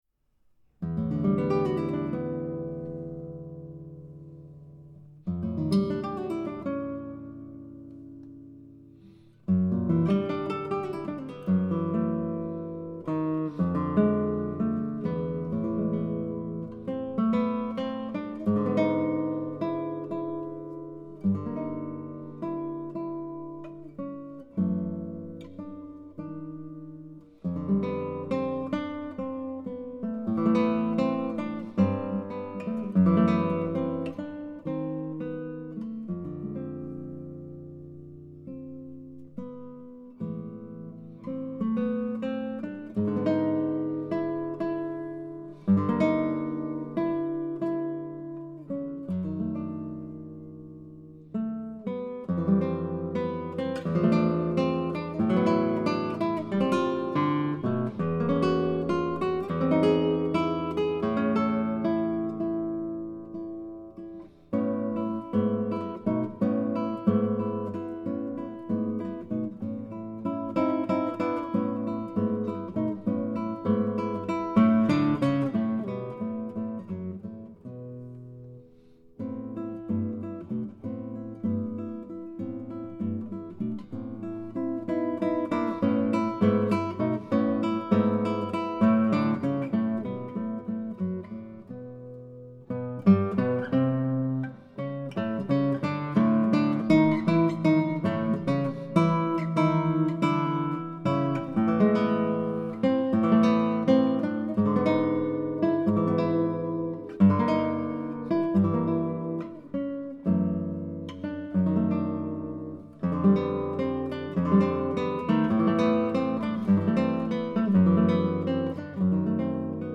Tags: Violão Solo